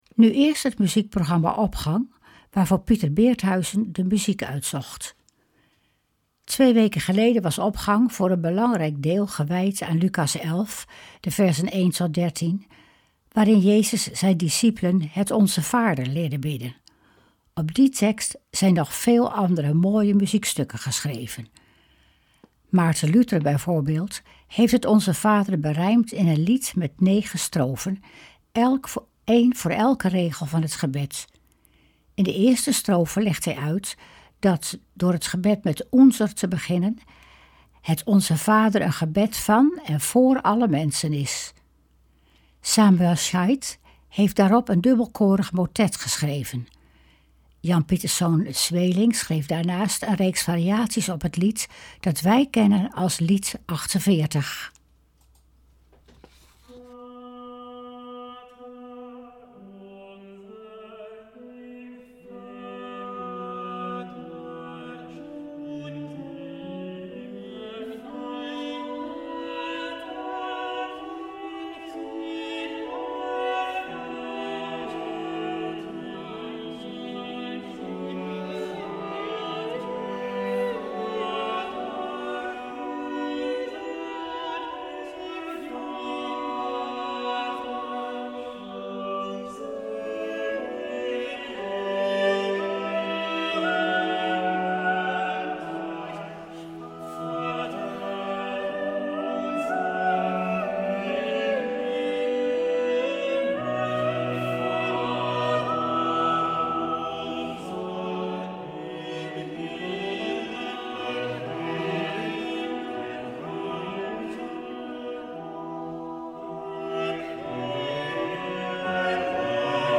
Opening van deze zondag met muziek, rechtstreeks vanuit onze studio.
Samuel Scheidt heeft daarop een dubbelkorig motet geschreven.
Sweelinck schreef daarnaast een reeks variaties op het lied, dat wij kennen als LvK 48.